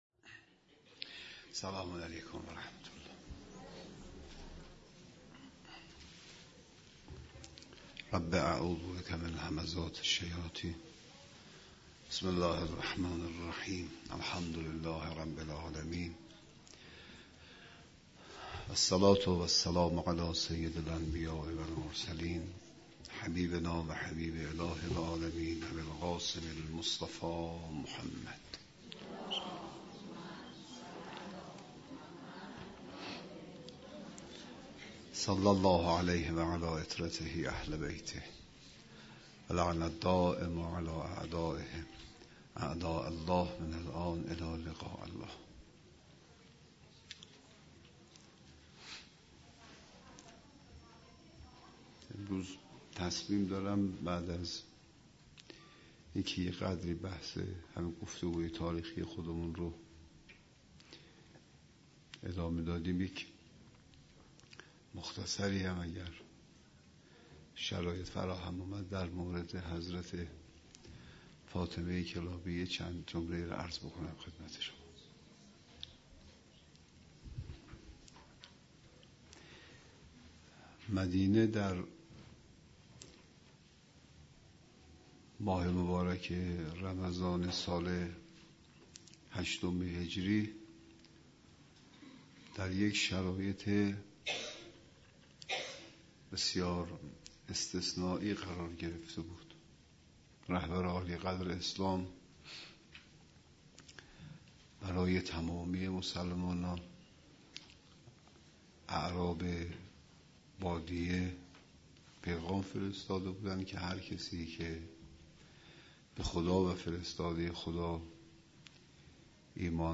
جلسات آموزشی :: مؤسسه قرآن و عترت علی بن موسی الرضا (ع)